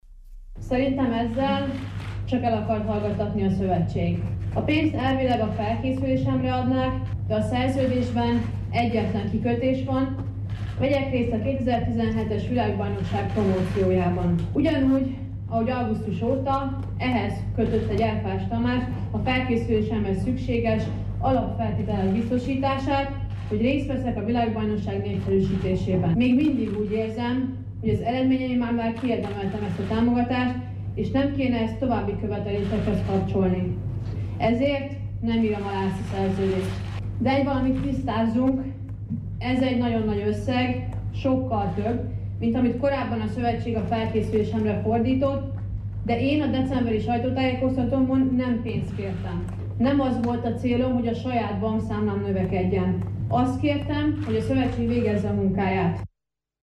A többszörös világ- és Európa-bajnok versenyző mai sajtótájékoztatóján azt mondta, a pénzt elvileg a felkészülésére adná a Magyar Úszó Szövetség, de a szerződésben egyetlen kikötés van, vegyen részt a 2017-es budapesti világbajnokság promóciójában. Hosszú Katinkát hallják.